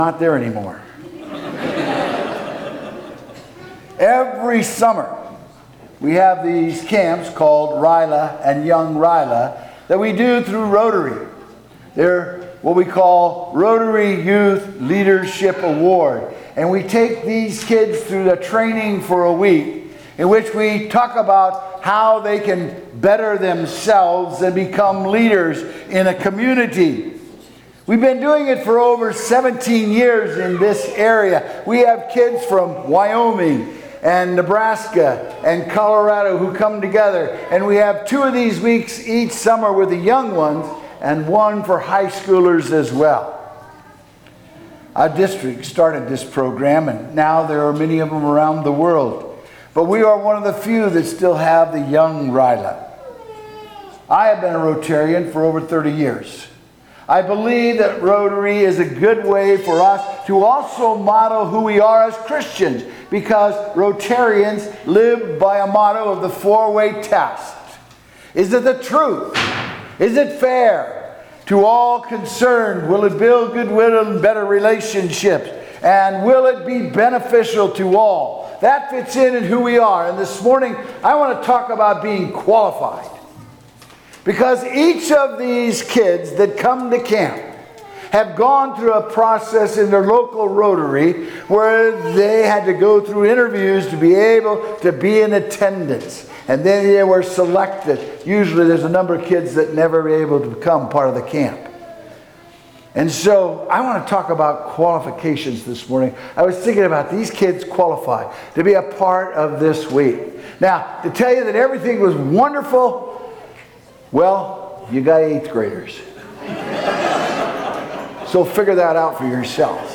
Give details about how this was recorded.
Fifth Sunday after Pentecost&nbsp Trinity Lutheran Church, Greeley, Colorado You've Been Qualified!